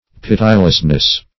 pitilessness - definition of pitilessness - synonyms, pronunciation, spelling from Free Dictionary
Pit"i*less*ness, n.